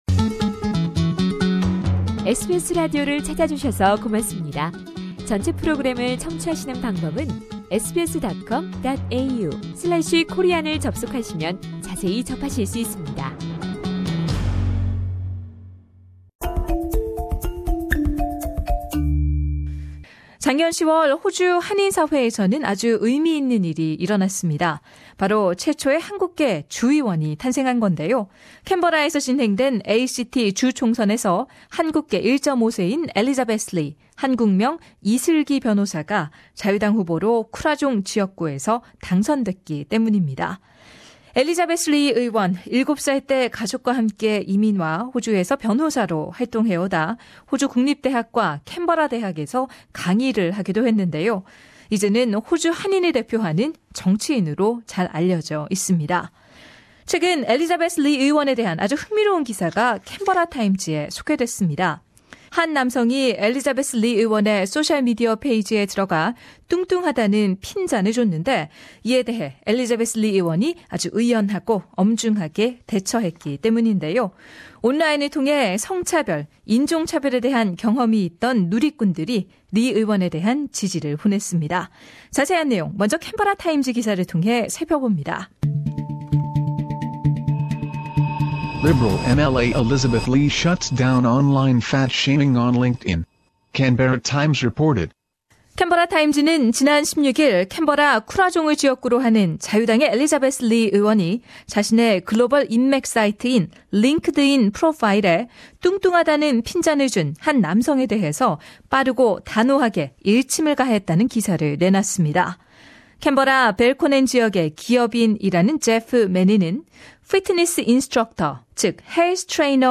리 의원은 22일 저희 SBS 라디오 한국어 프로그램과의 인터뷰를 통해 이 사건에 대해서 이야기를 나눴는데요.
리 의원은 또한 호주 한인 동포들께 한국계 정치인으로써의 자신의 각오와 감사의 인사를 한국어로 전하기도 했습니다.